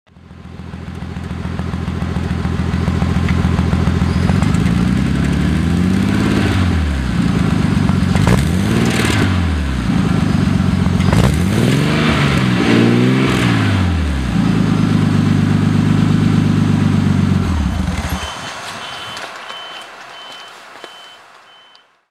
これは私もかなり悩まされた症状なんですが、 直列6気筒エンジンなはずなのに、なぜかスバルやポルシェのような水平対気筒エンジンの音がする。
アイドリング時はこのような音がします